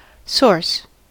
source: Wikimedia Commons US English Pronunciations
En-us-source.WAV